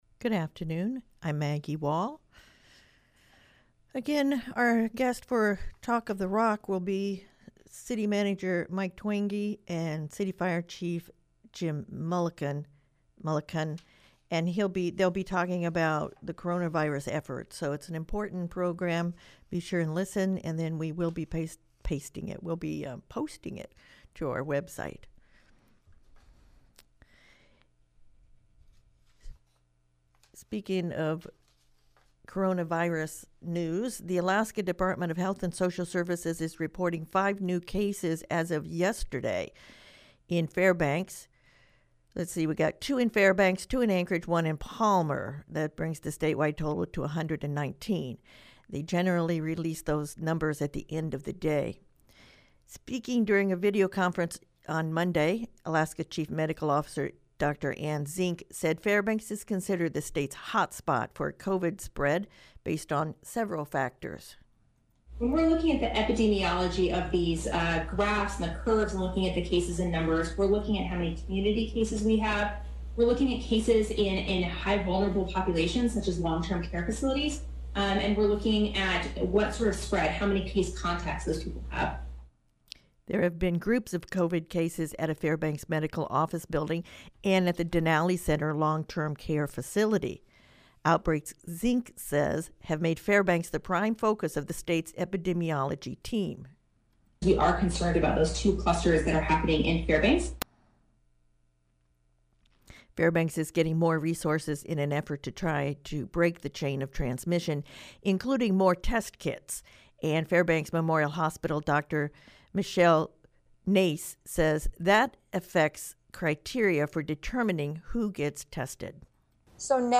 Noon Newscast — March 31, 2020